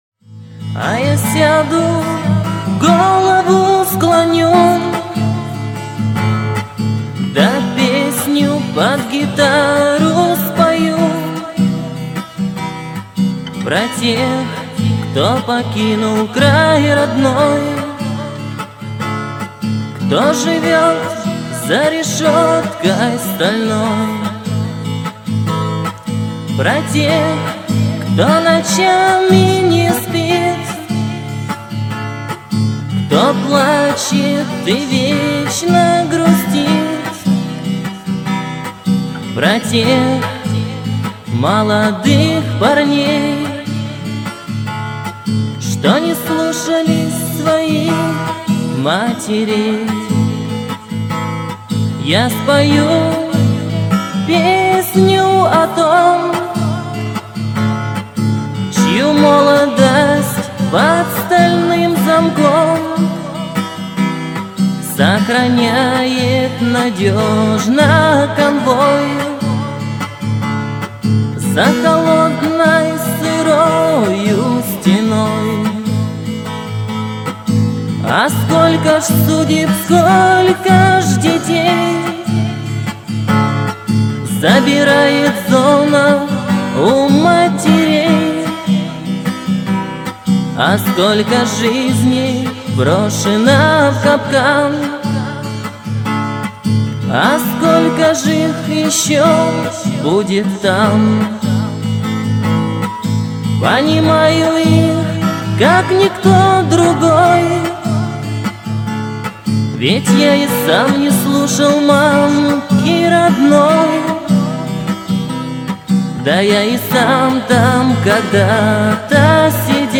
Pesnya_pod_gita___A_ya_syadu_golovu_sklonyu.mp3